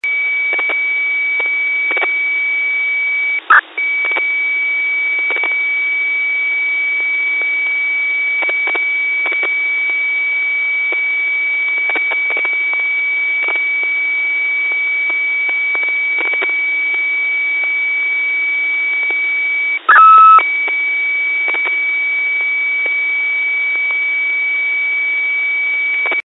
UIC 751-3 Audio signal